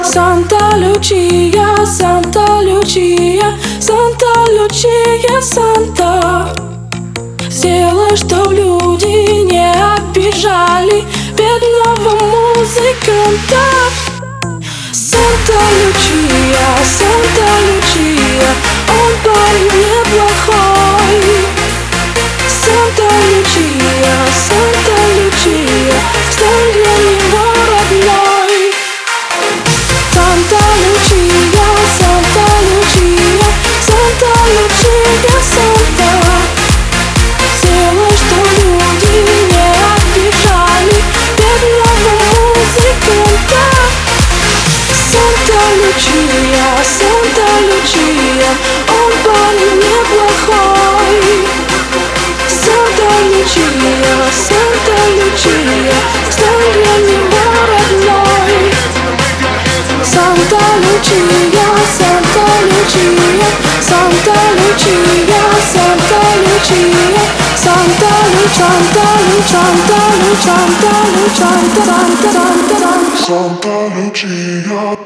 • Качество: 320, Stereo
remix